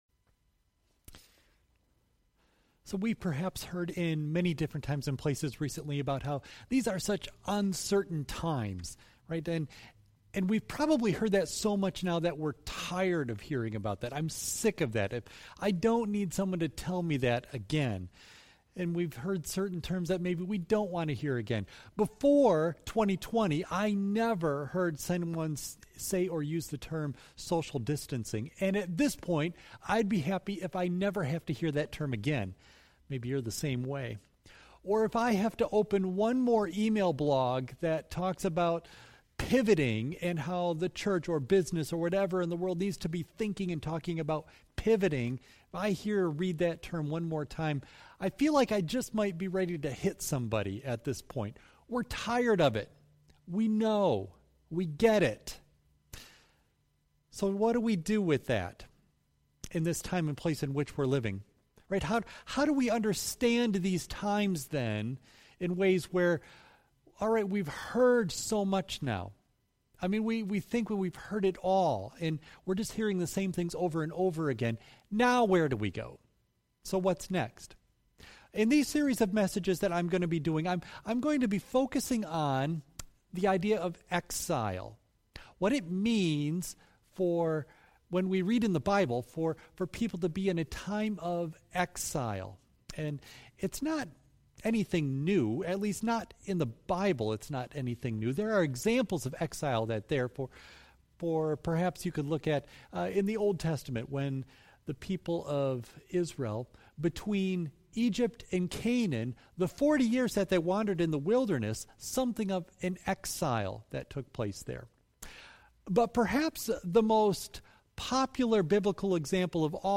Worship Service June 7 Audio only of message